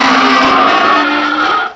pokeemerald / sound / direct_sound_samples / cries / scolipede.aif